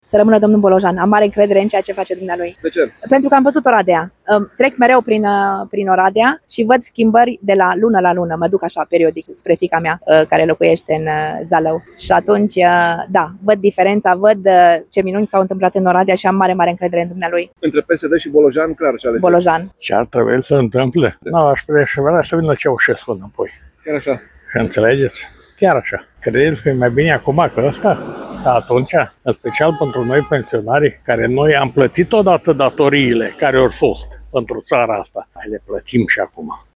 „Aș prefera să vină Ceaușescu înapoi”, spune un bărbat